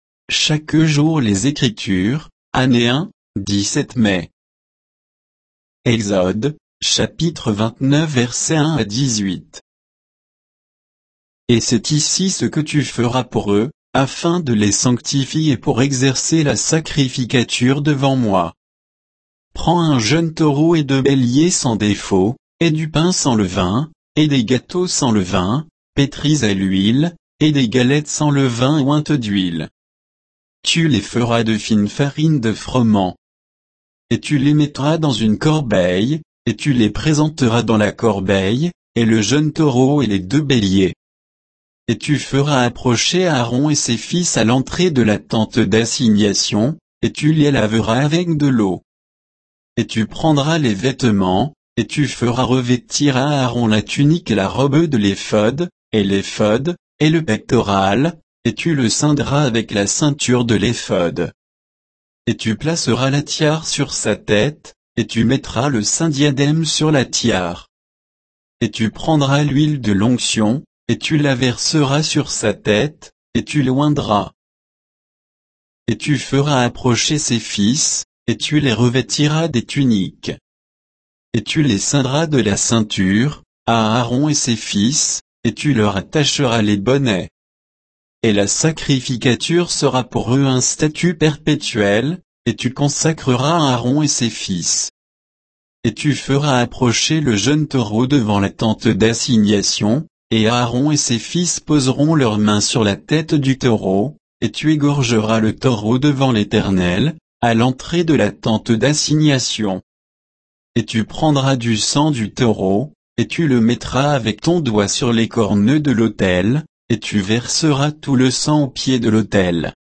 Méditation quoditienne de Chaque jour les Écritures sur Exode 29